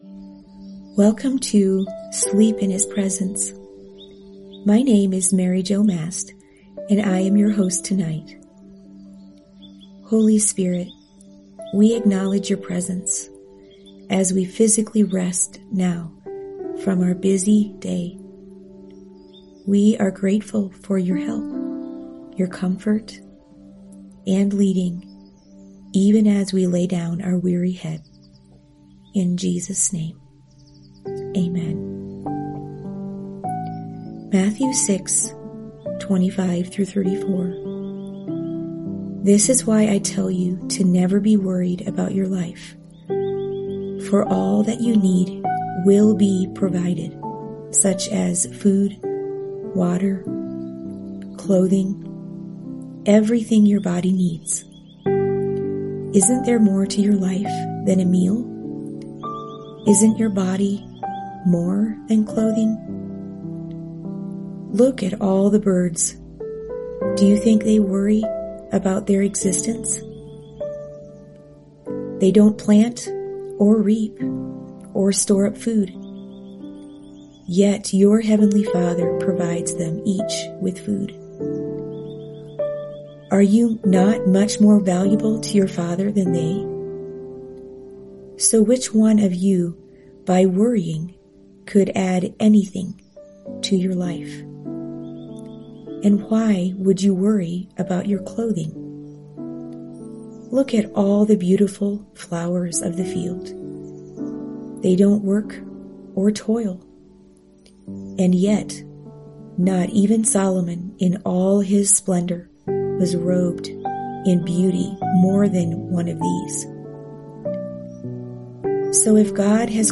In this 6-day devotional, you’ll be gently led into the presence of God through heartfelt prayers, encouraging words, and powerful Scripture. Each night, a different host guides you to release your worries, embrace God’s love, and fall asleep knowing He is near.